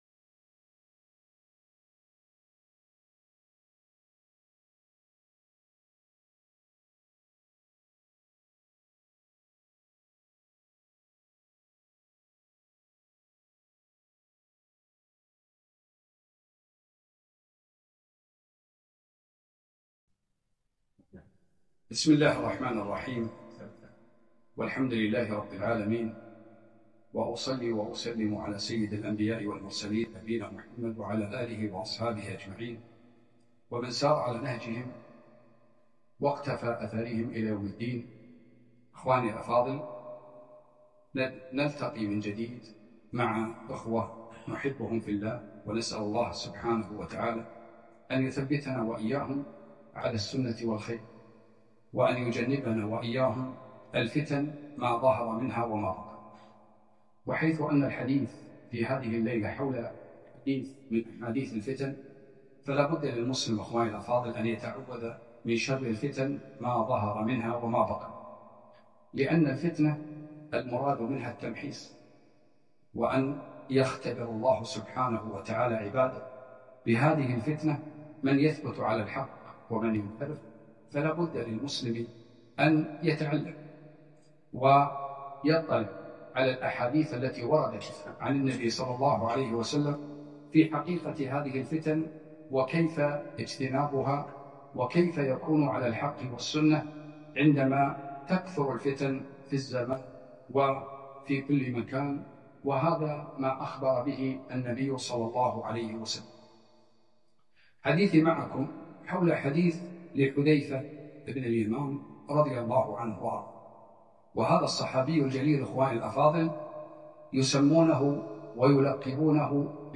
كلمة - توجيهات نبوية في زمن الفتن